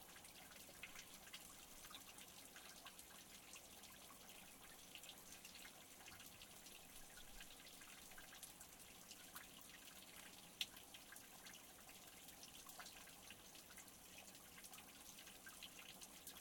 Water rolling down off cliff
Duration - 16 s Environment - Open air, breathing, birds, and flies, wind vibrations of hand hold mike. Cars nearby. Description - Water rolling, dripping, splashes, hitting surfaces, expands outwards flows into larger pool of water, vibrates, splashes different sounds.